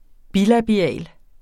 Udtale [ ˈbilabiˌæˀl ]